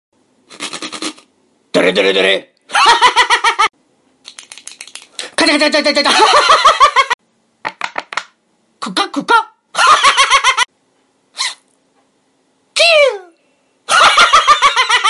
copying sounds 📢 sound effects free download